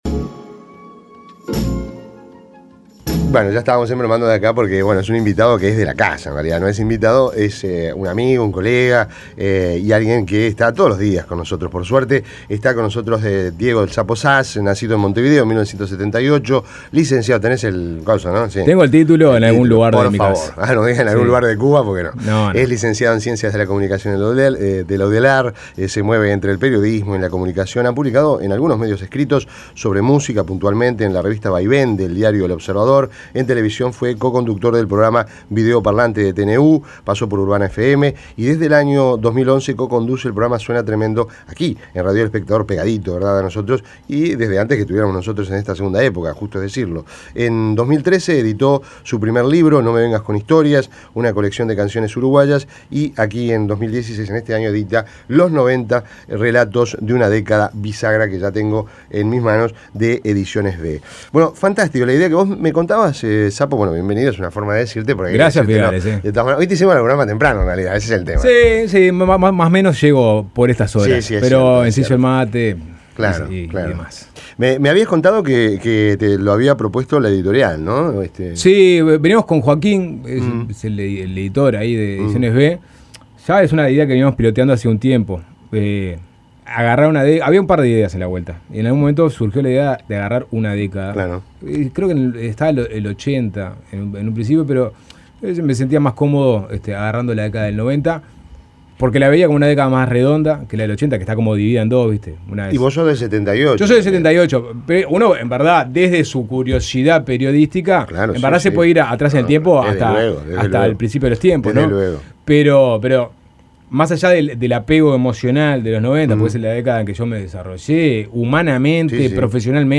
Entrevista De la Cicciolina al .uy Imprimir A- A A+ El periodista